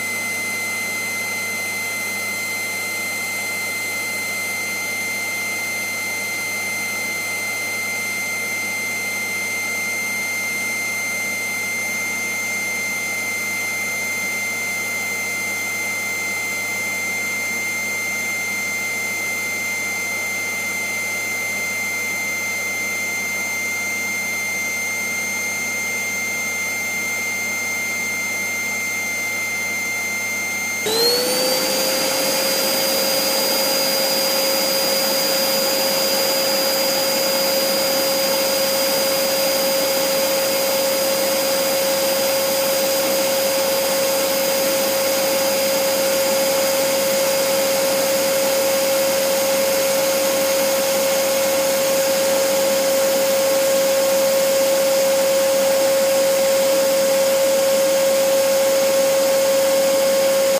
描述：低功率吸尘器的声音。 30秒后，它变为全功率
Tag: 真空 胡佛吸尘器